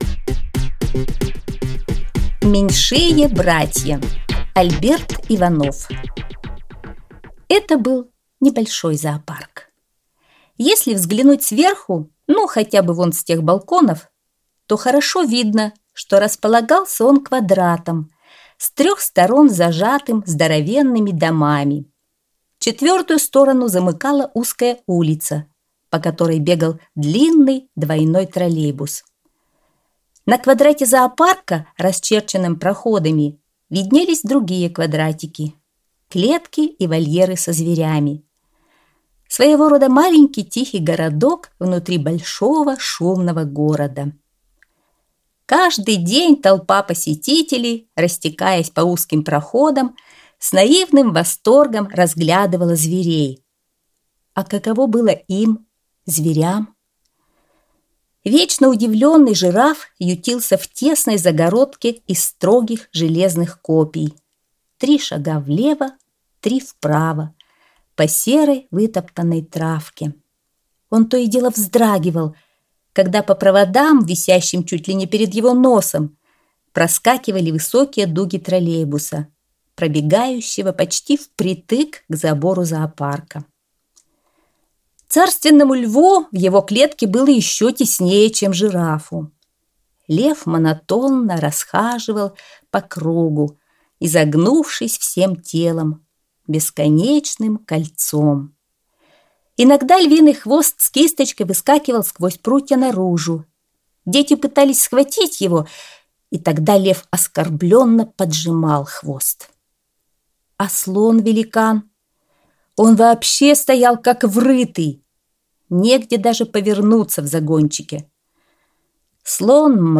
Аудиосказка «Меньшие братья»